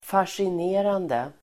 Ladda ner uttalet
Uttal: [fasjin'e:rande]
fascinerande.mp3